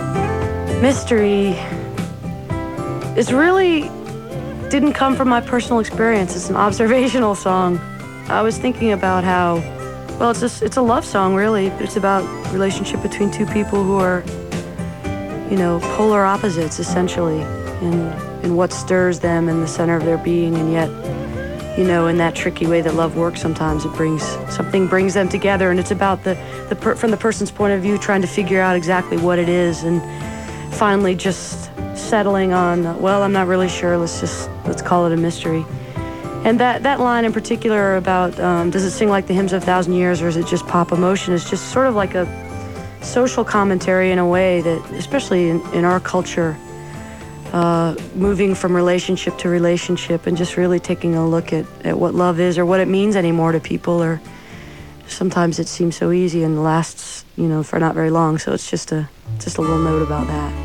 05. interview (1:00)